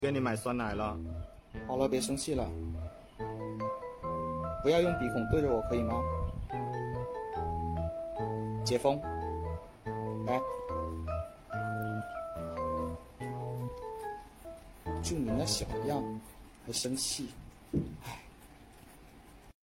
If you let an angry seal open its mouth!